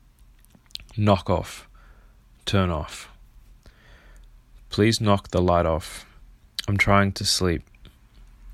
Knock off 消す ネイティブ音声♪ | 英会話の表現やイディオムを一日一分で学ぶ 一日一英会話 | マンツーマン英会話スクールのIHCWAY
英語ネイティブによる発音は下記のリンクをクリックしてください。